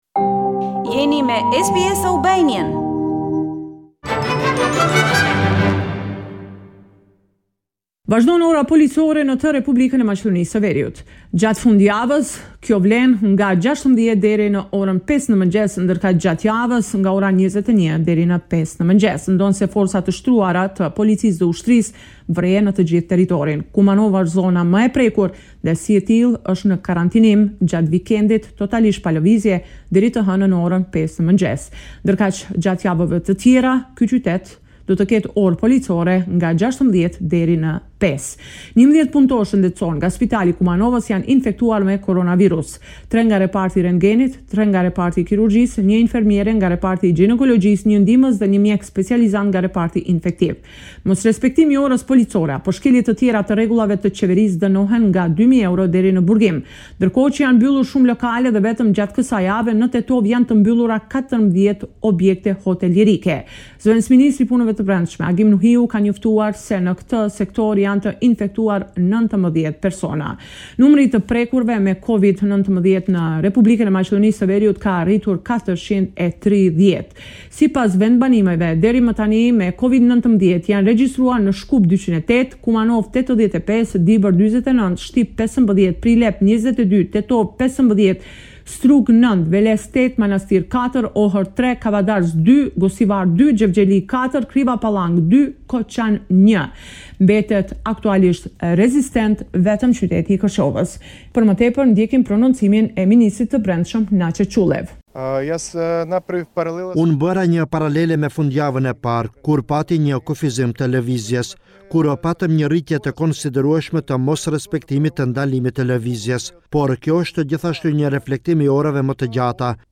This is a report summarising the latest developments in news and current affairs in North Macedonia